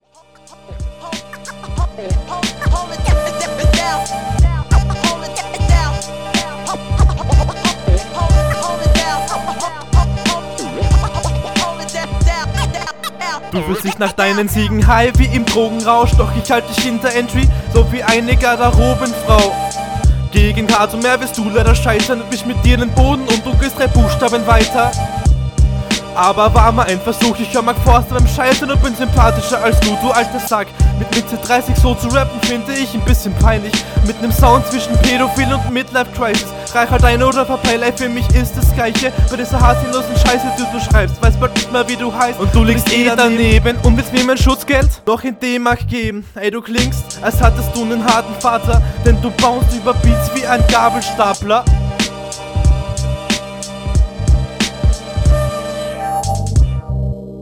Sound nicht so ideal. beim Mix ist noch einiges an Luft nach oben und du …
Auch hier leider wie oft angesprochen zu schwach abgemischt.